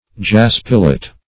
Search Result for " jaspilite" : The Collaborative International Dictionary of English v.0.48: Jaspilite \Jas"pi*lite\, n. [Jasper + -lite.]